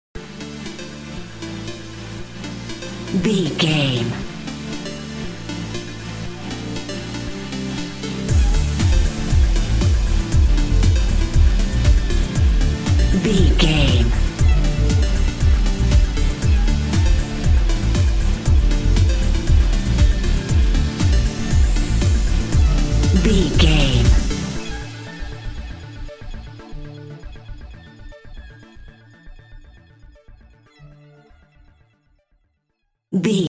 Pop Chart Electronic Dance Music 15 Sec.
Fast paced
In-crescendo
Aeolian/Minor
groovy
uplifting
driving
energetic
bouncy
synthesiser
drum machine
house
electro dance
synth leads
synth bass
upbeat